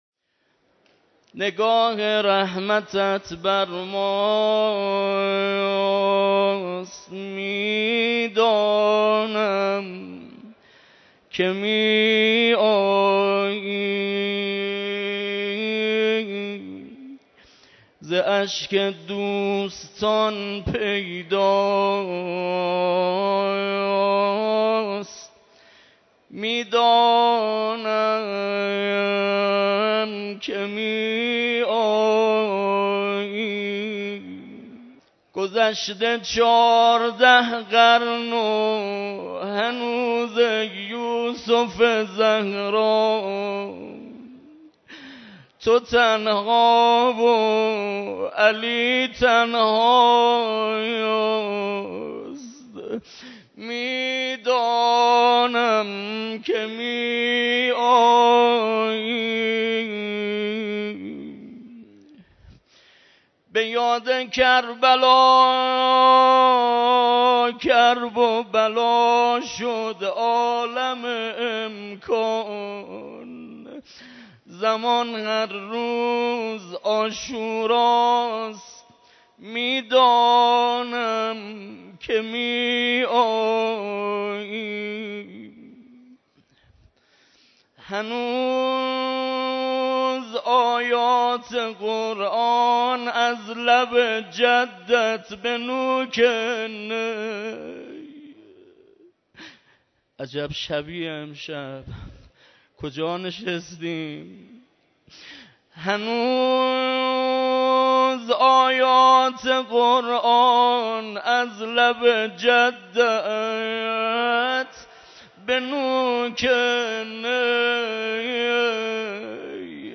مراسم عزاداری شب تاسوعای حسینی
مراسم عزاداری شب تاسوعای حسینی (ع) برگزار شد